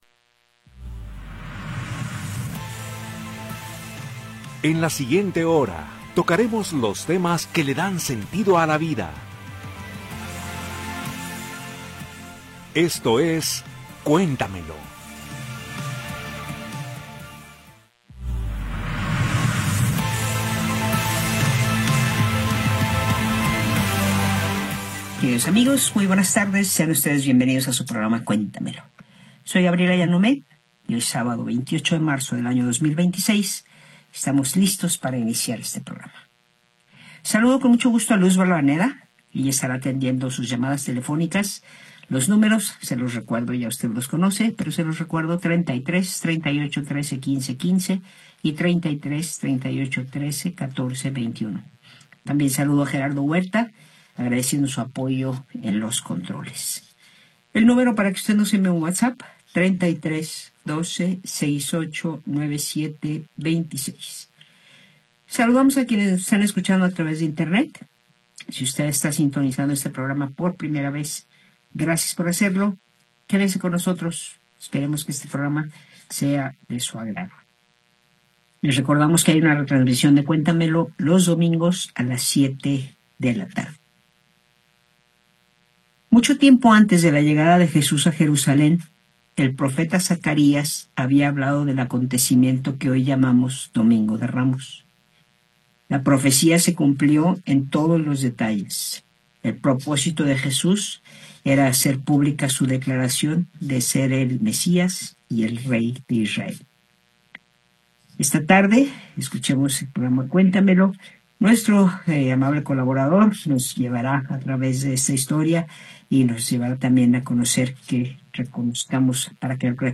en vivo con los temas que dan sentido a la vida.